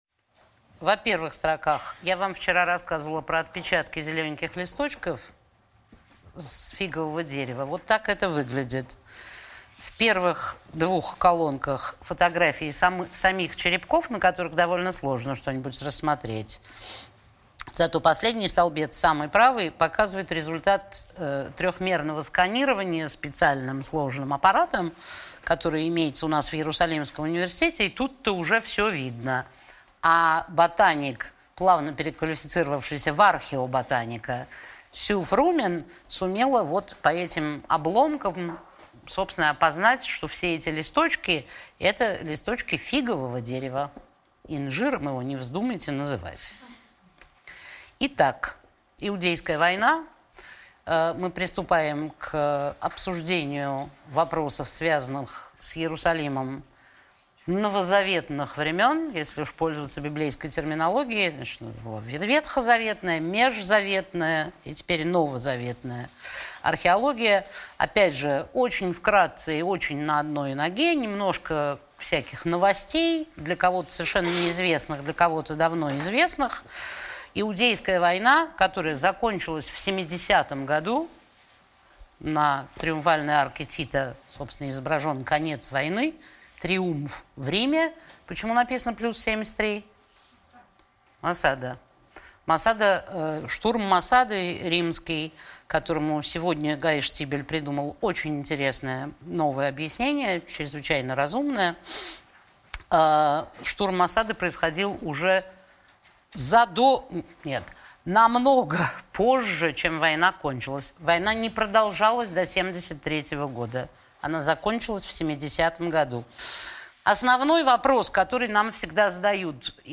Аудиокнига Археологические тайны Иерусалима. Часть 4 | Библиотека аудиокниг